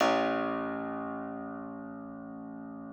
53l-pno01-A-1.aif